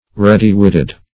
Ready-witted \Read"y-wit`ted\ (r[e^]d"[y^]-w[i^]t`t[e^]d), a. Having ready wit.